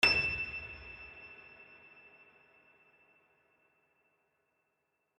piano-sounds-dev
e6.mp3